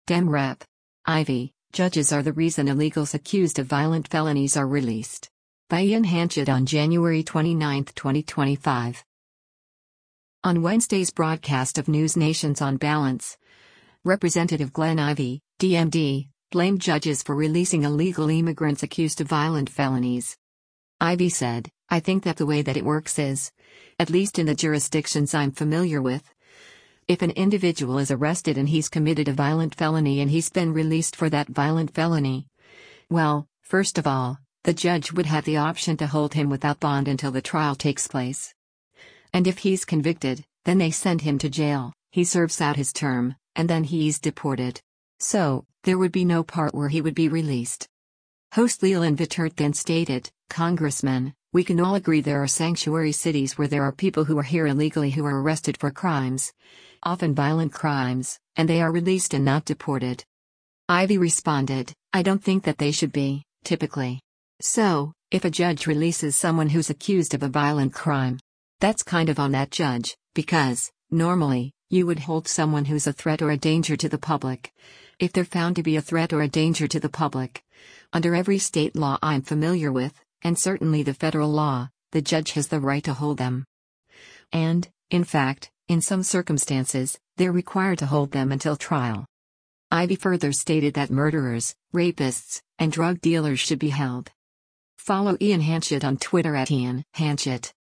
On Wednesday’s broadcast of NewsNation’s “On Balance,” Rep. Glenn Ivey (D-MD) blamed judges for releasing illegal immigrants accused of violent felonies.
Host Leland Vittert then stated, “Congressman, we can all agree there are sanctuary cities where there are people who are here illegally who are arrested for crimes, often violent crimes, and they are released and not deported.”